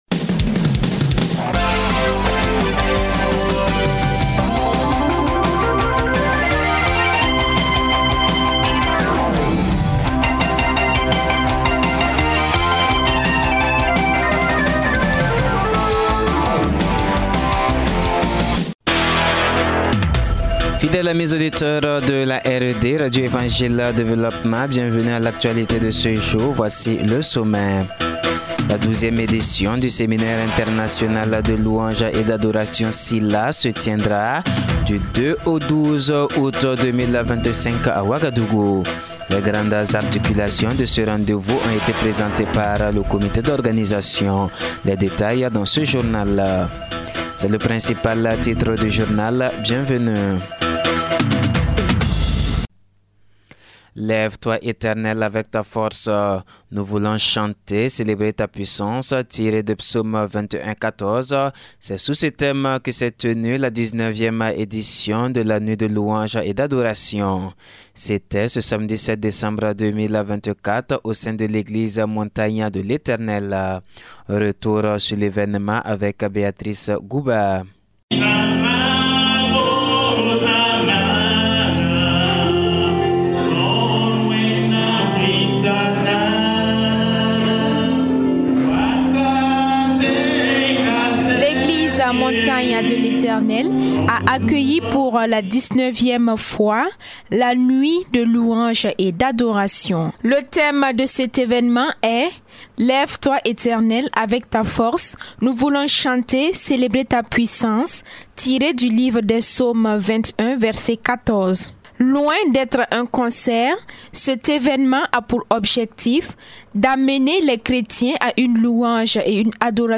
Journal parlé RED du mardi 10 décembre 2024